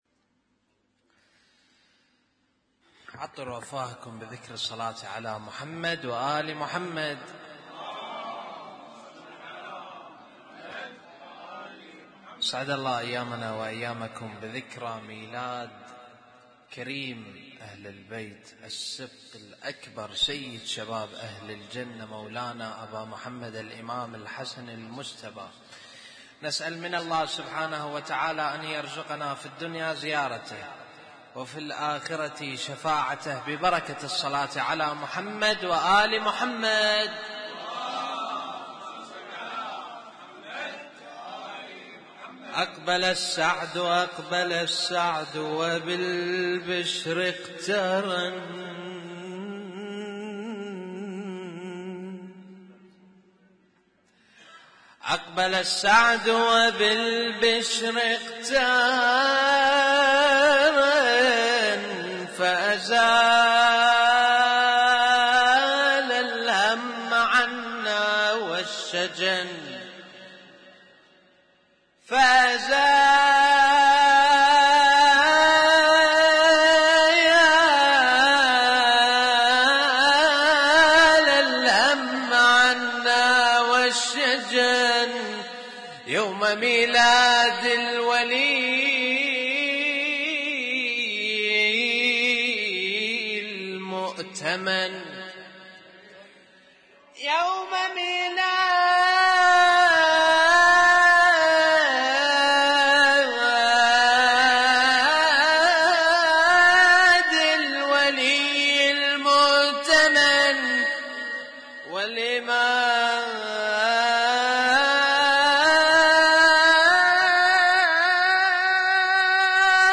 Husainyt Alnoor Rumaithiya Kuwait
اسم التصنيف: المـكتبة الصــوتيه >> المواليد >> المواليد 1440